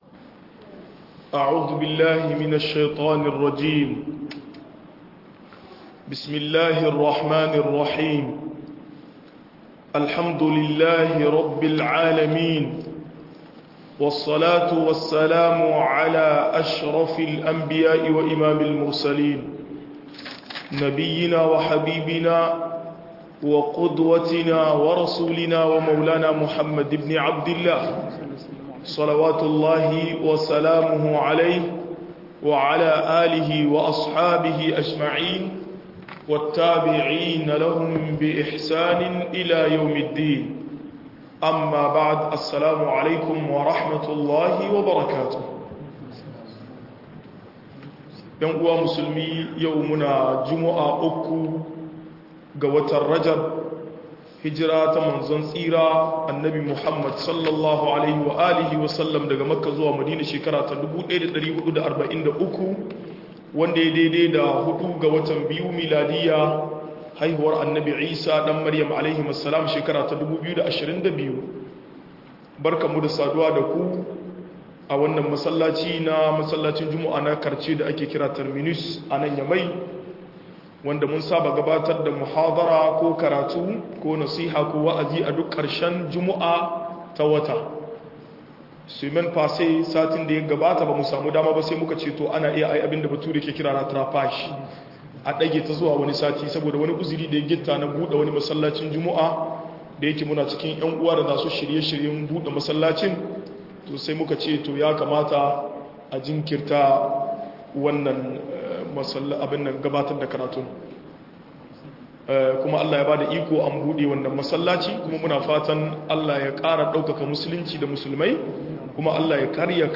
Bada dalili da tasirin sa a tarbiyya - MUHADARA